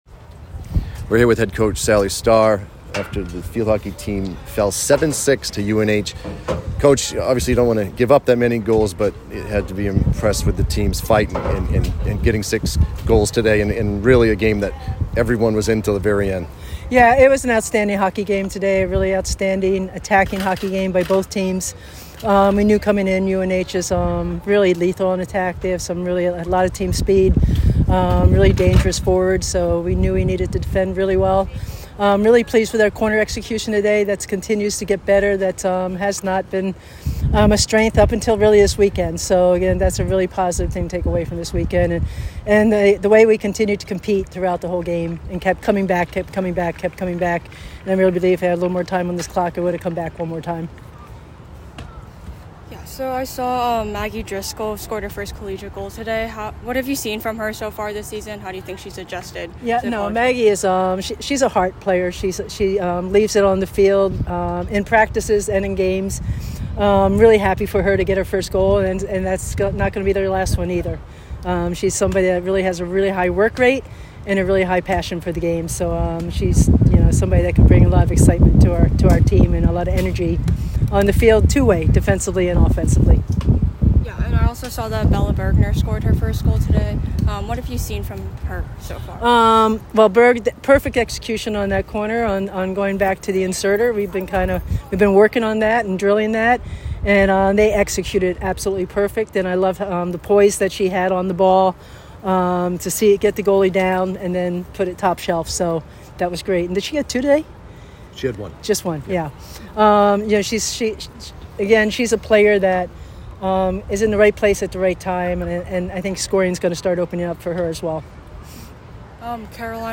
UNH Postgame Interview